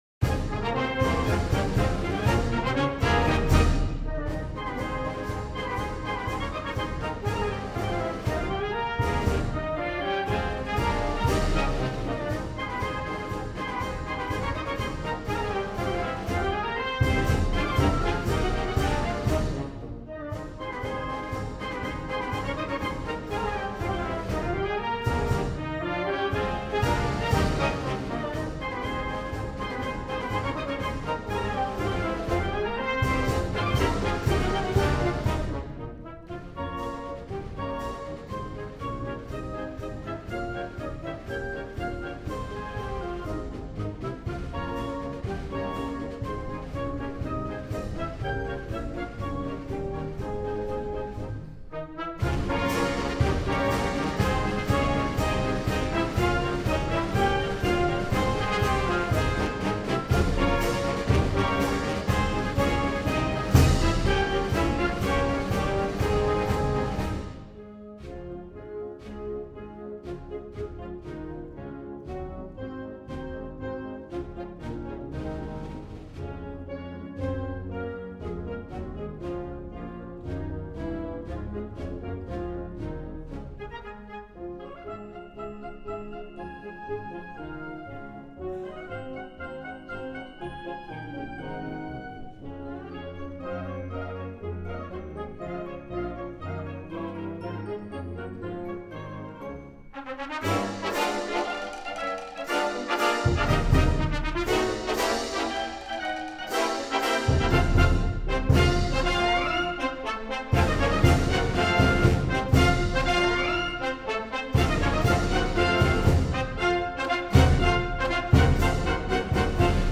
Sousa dedicated the march to all college students past, present, and future.